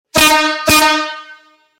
Buzinas de Torcida
• 01 ou diversas cornetas conforme modelo;
• Intensidade sonora 130db;
• Acionamento através de bomba manual;